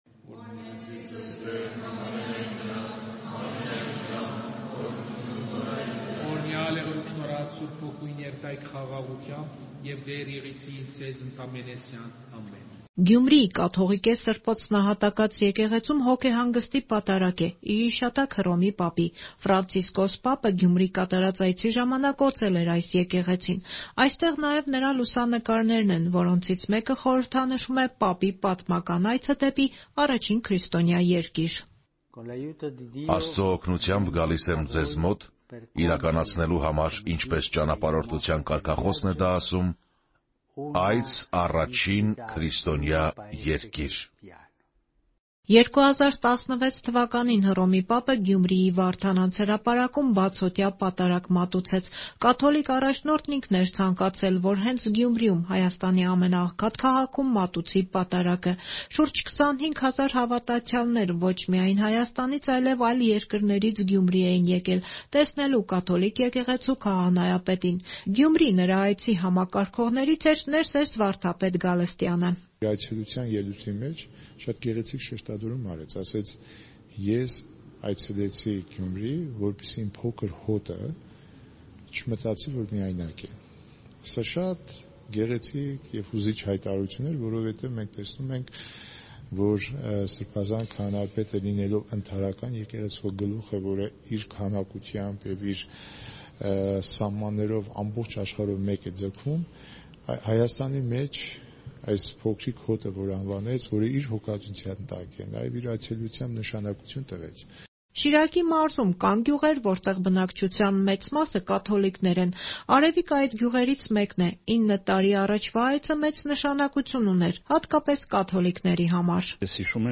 Ռեպորտաժներ
Գյումրիի կաթողիկե Սբ. Նահատակաց եկեղեցում հոգեհանգստի պատարագ՝ ի հիշատակ Ֆրանցիսկոս պապի